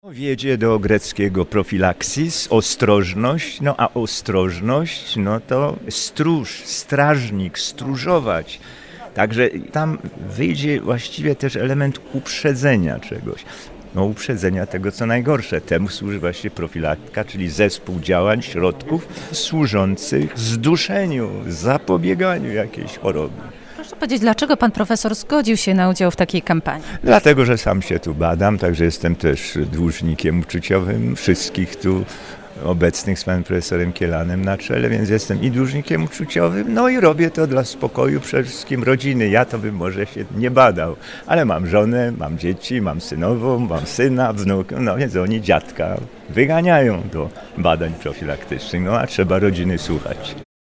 Do udziału w profilaktyce zachęca prof. Jan Miodek, wrocławianin i językoznawca. Tłumaczy źródłosłów słowa „profilaktyka” i mówi o swoim udziale w kampanii.